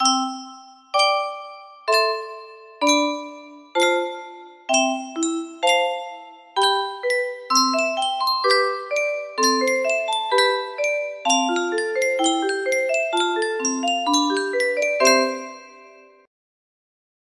Yunsheng Music Box - Pachelbel Canon in D Y269 music box melody
Full range 60